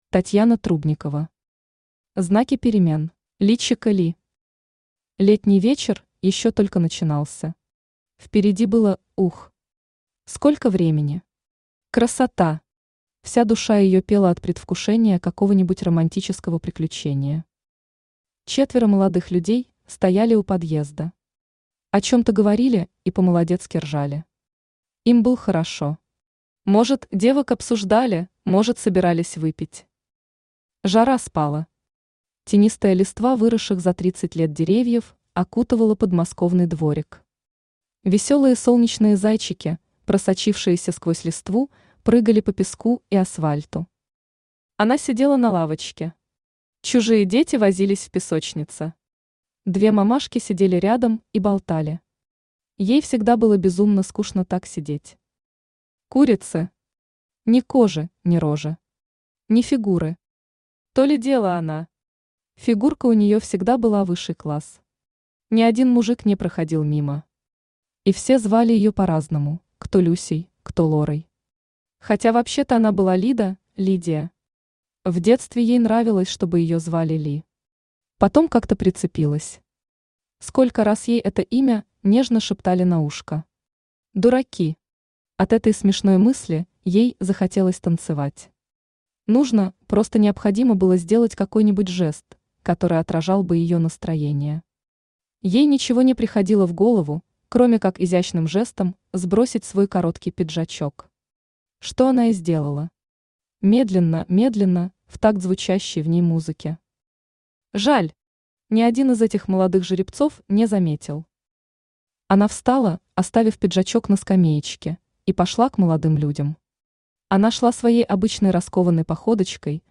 Аудиокнига Знаки перемен | Библиотека аудиокниг
Aудиокнига Знаки перемен Автор Татьяна Юрьевна Трубникова Читает аудиокнигу Авточтец ЛитРес.